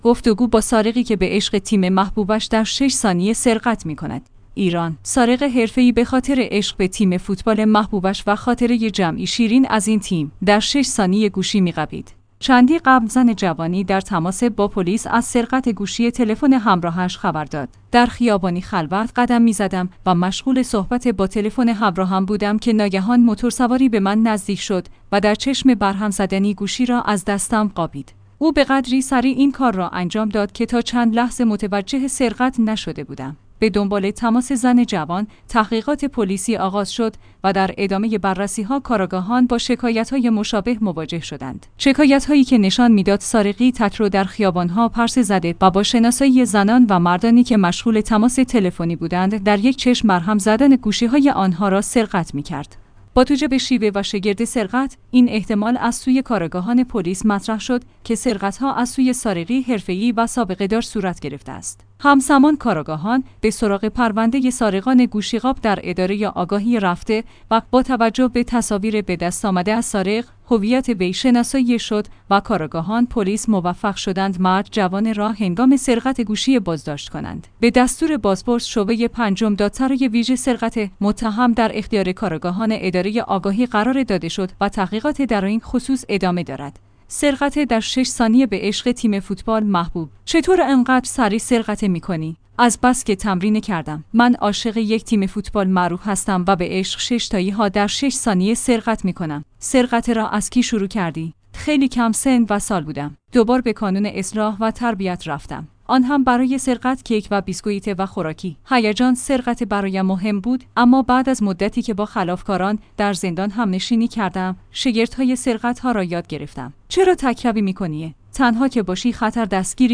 گفتگو با سارقی که به عشق تیم محبوبش در 6 ثانیه سرقت می کند!